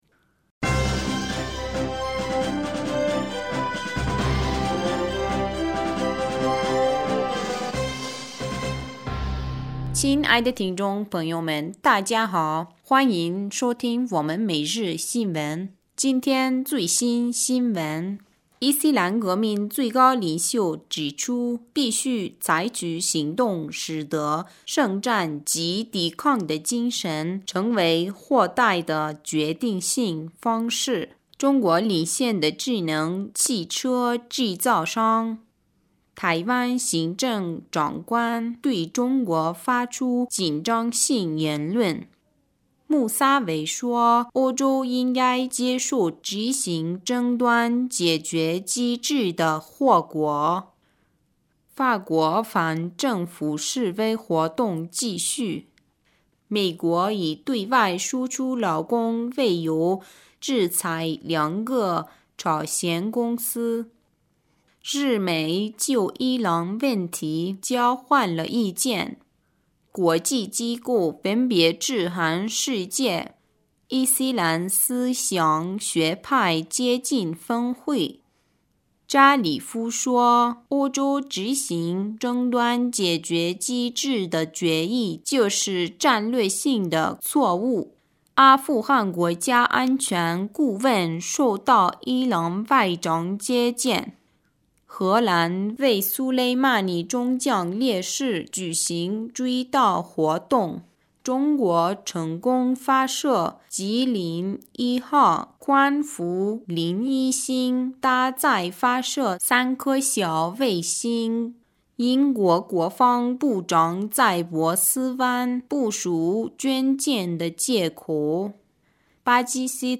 2020年1月15日 新闻